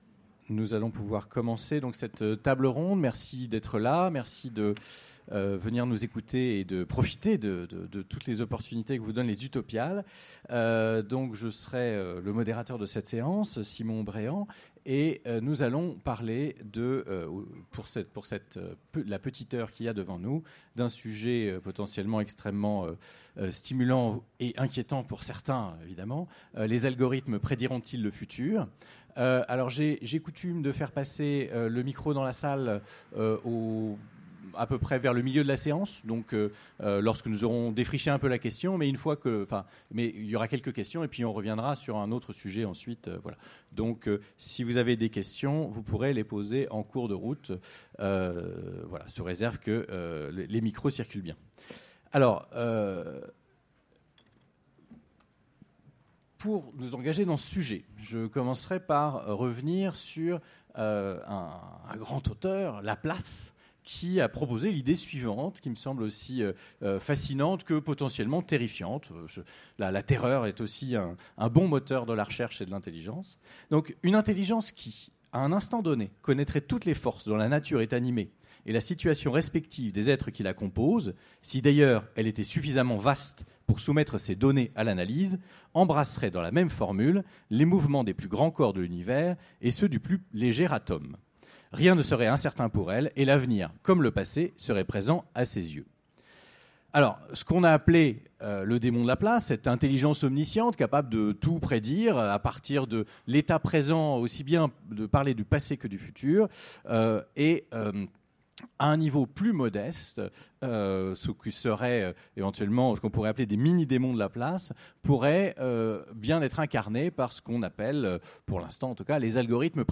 Utopiales 2017 : Conférence Les algorithmes prédiront-ils le futur ?